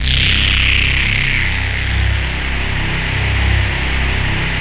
BASS18  01-R.wav